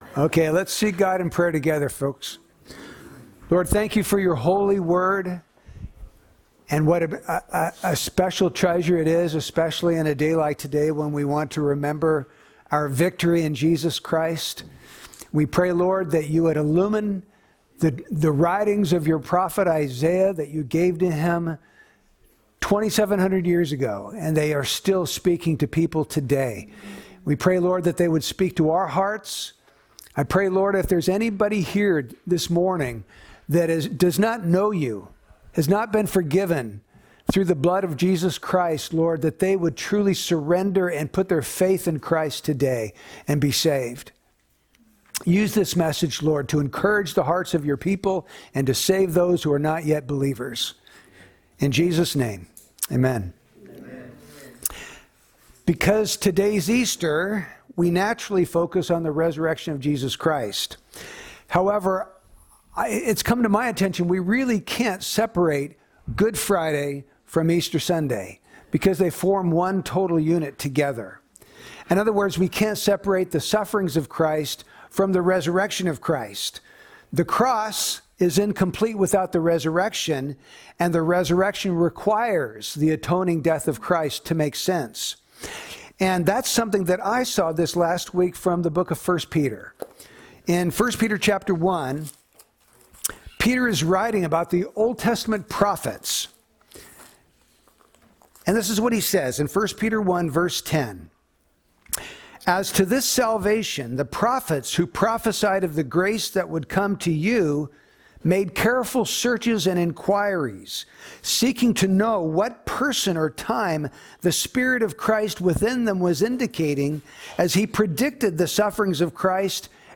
Series: Holiday Messages